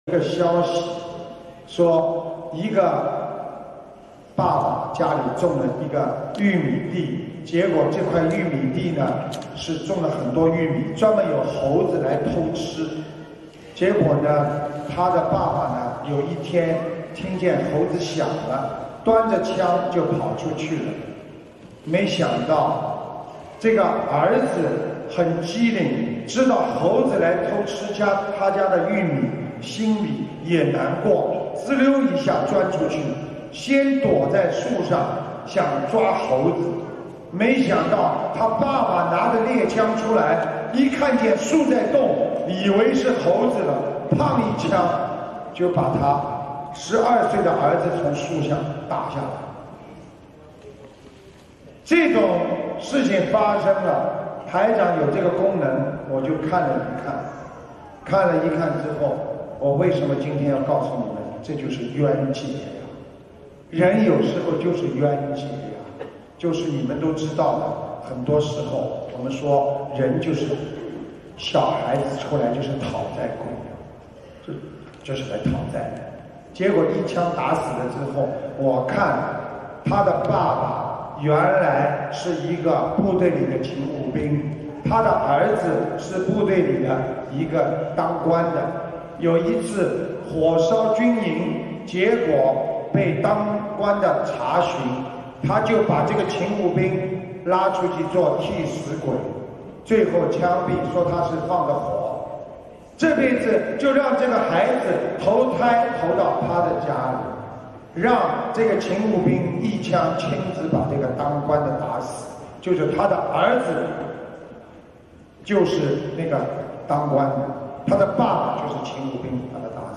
音频：误杀·师父讲小故事大道理